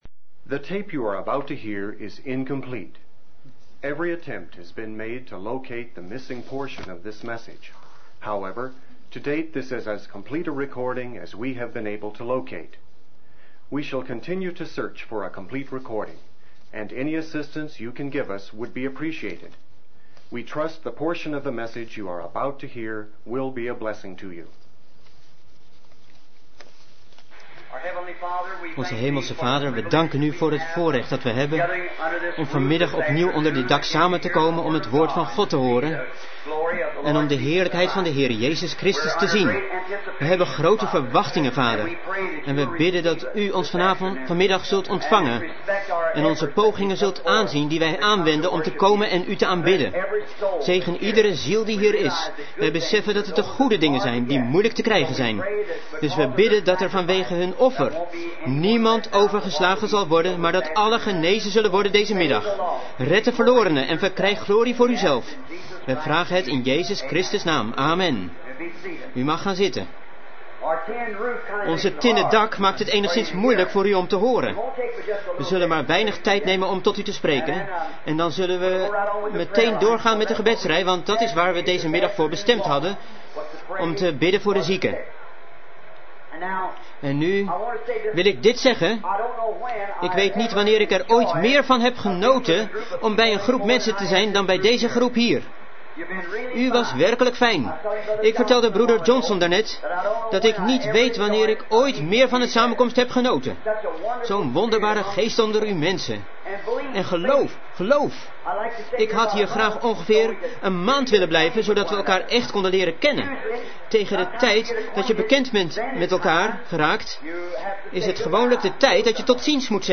De vertaalde prediking "A trial" door William Marrion Branham gehouden in Club barn, Louisville, Mississippi, USA, 's middags op zondag 05 april 1964